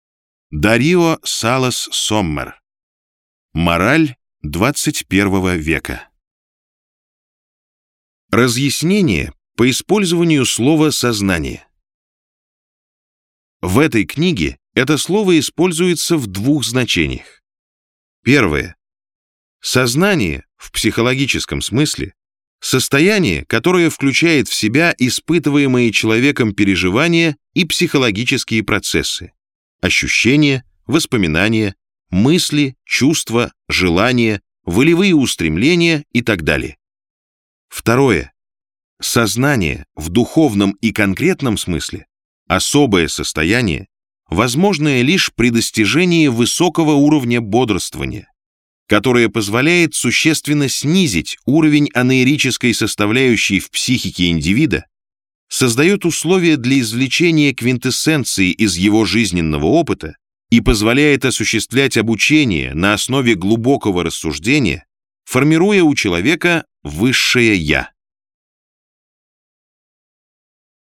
Аудиокнига Мораль XXI-го века | Библиотека аудиокниг